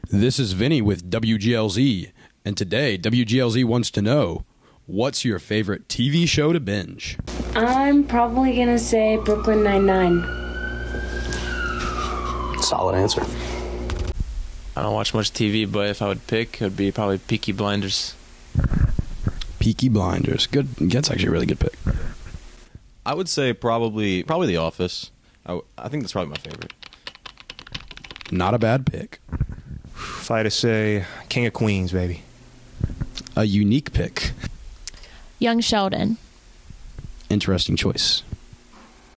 WLU students are answering the question, what is your favorite TV series to binge.